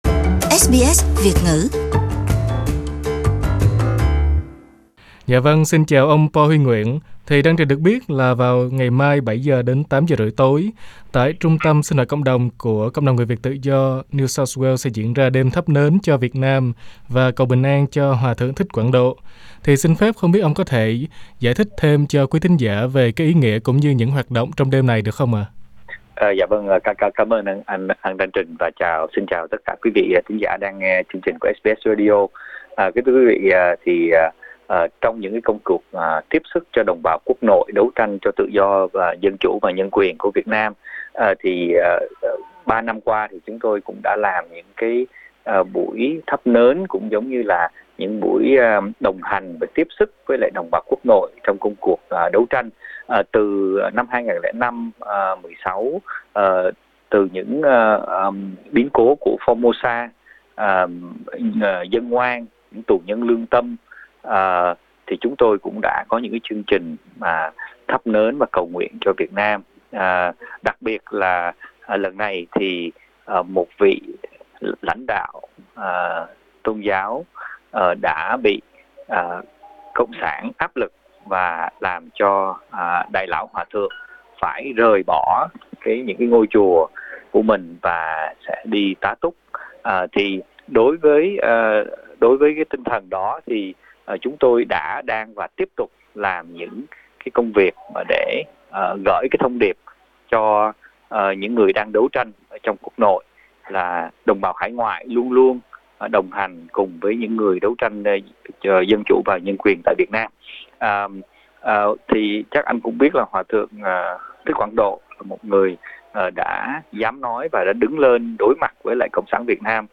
SBS Vietnamese phỏng vấn